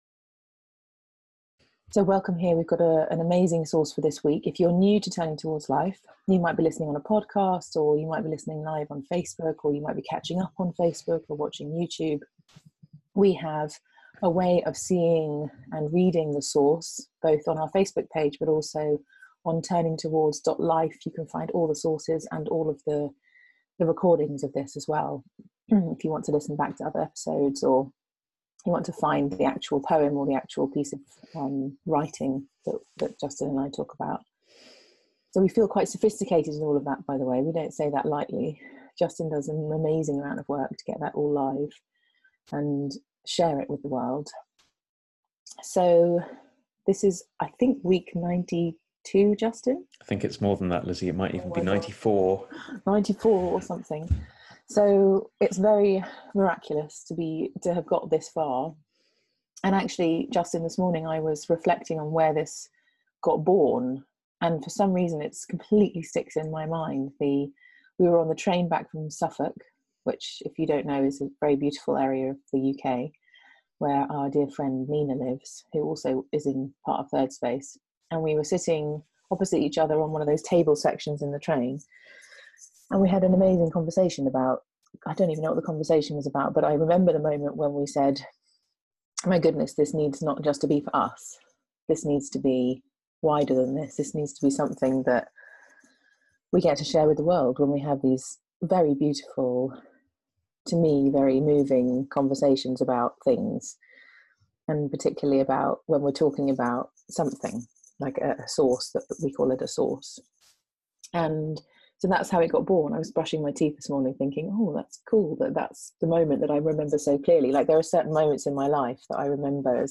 Find us on FaceBook to watch live and join in the lively conversation on this episode. We’re also on YouTube, and as a podcast on Apple, Google and Spotify We easily make two big mistakes in making sense of our lives, mistakes which add to whatever difficulties we already find ourselves in.